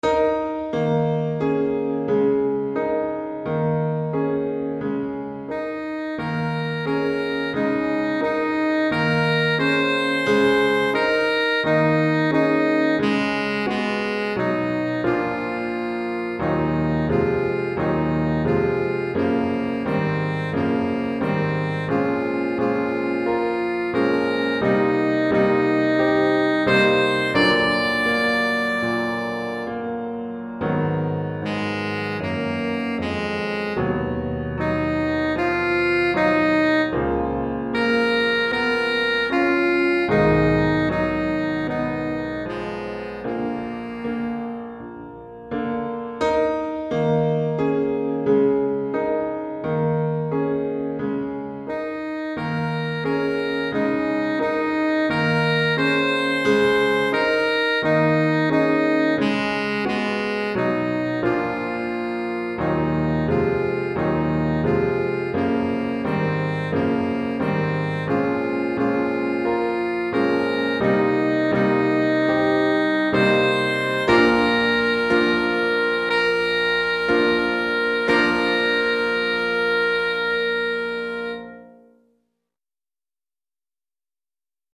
pour saxophone alto et piano DEGRE CYCLE 1